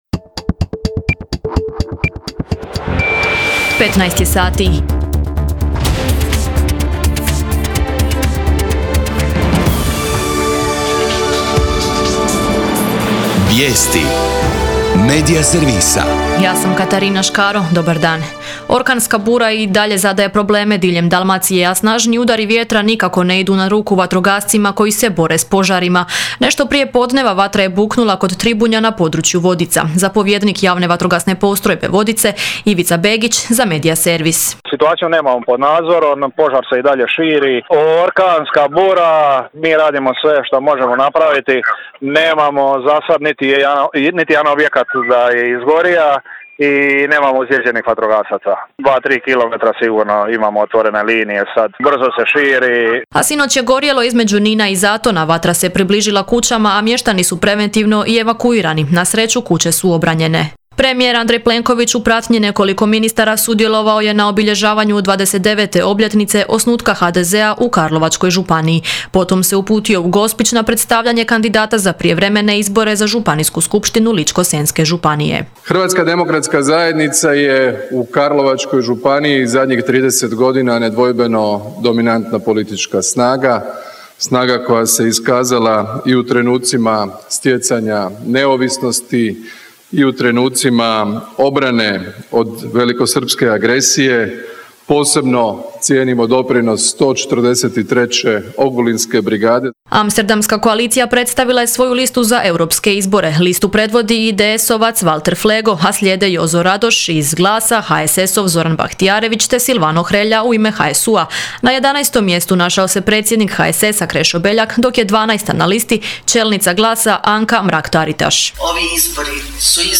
VIJESTI U 15